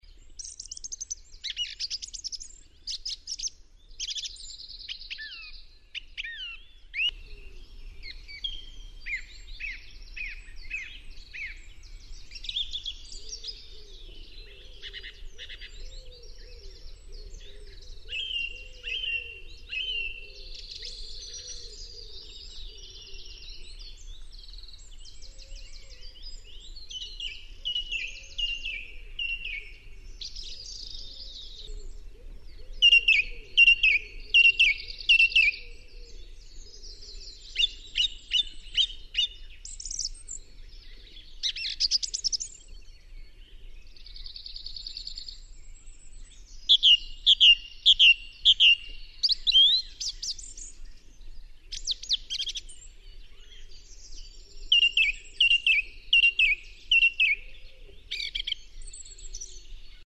Grive musicienne
Turdus philomelos
Chant
Grive_musicienne.mp3